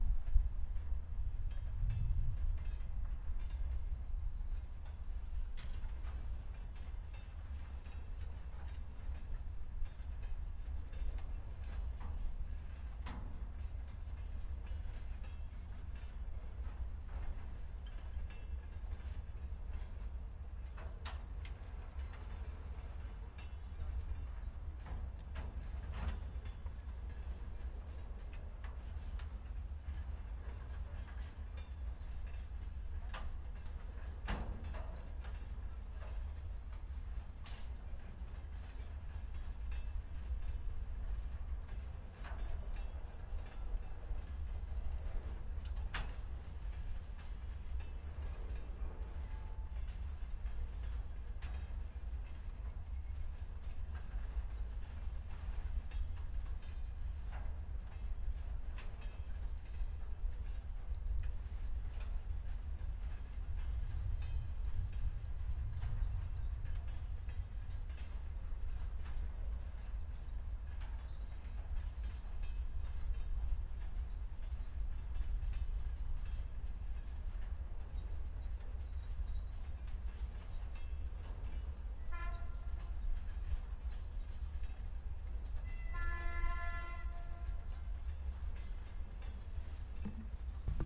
列车 " 列车0005
描述：在乌克兰利沃夫铁路附近记录会议。使用Zoom H1录制
Tag: 场记录 列车 铁路